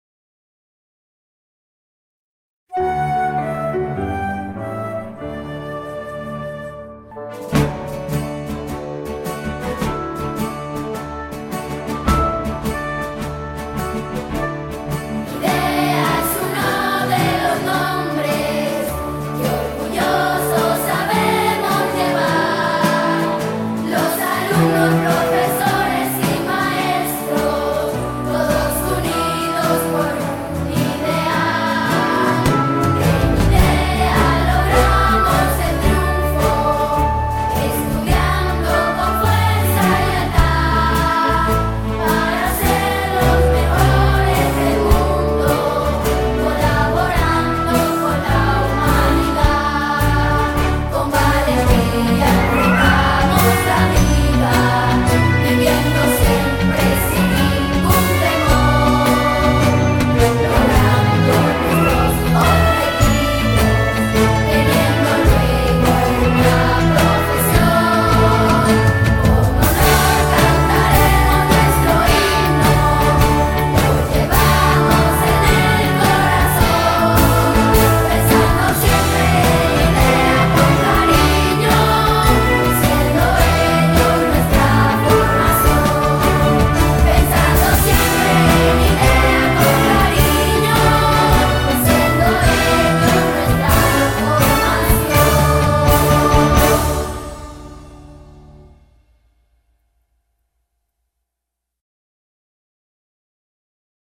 himno.ogg